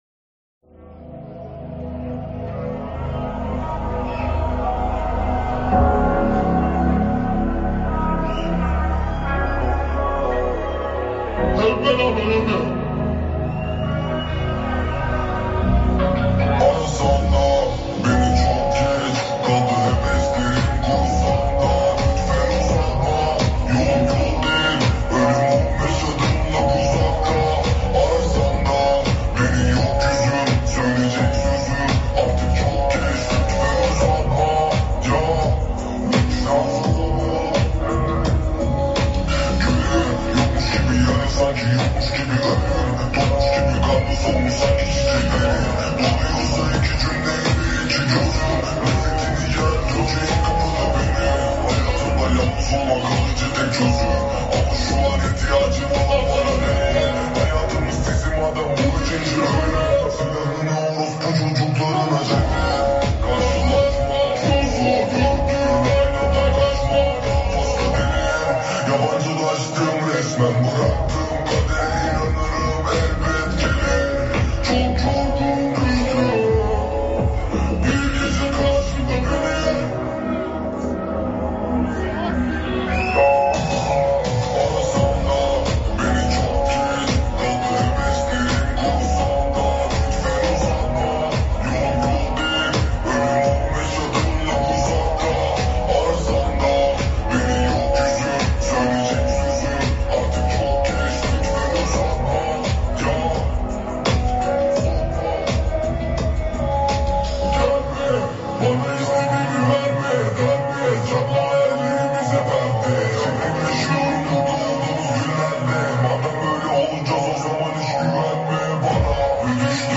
Slowed - Reverb